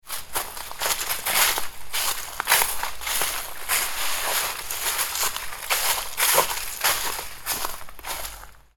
Footsteps On Dry Leaves Sound Effect
A person walks on dry autumn leaves on a windy day in nature. Enjoy the crisp crunch of footsteps combined with the sound of rustling leaves outdoors. Human sounds.
Footsteps-on-dry-leaves-sound-effect.mp3